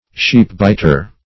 Search Result for " sheepbiter" : The Collaborative International Dictionary of English v.0.48: Sheepbiter \Sheep"bit`er\, n. One who practices petty thefts.